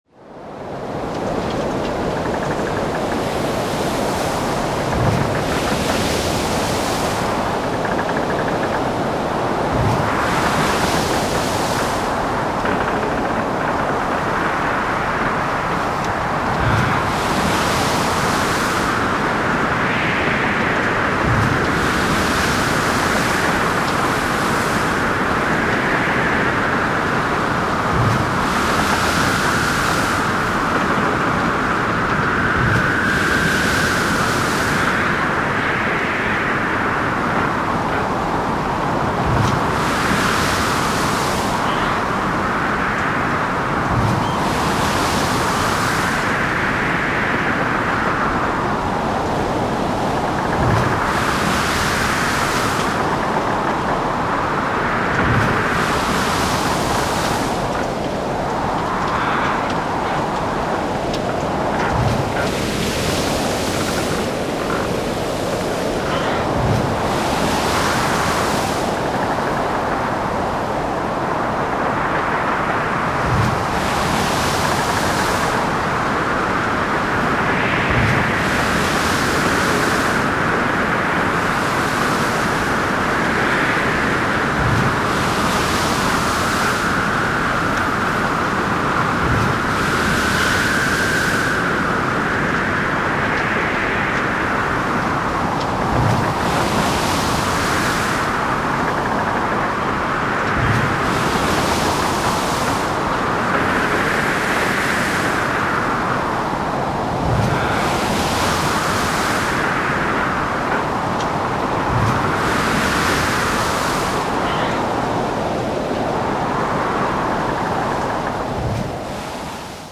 Громовые раскаты, завывание ветра и шум ливня создают эффект присутствия.
Грохот мощного шторма в океане с брызгами волн на корабле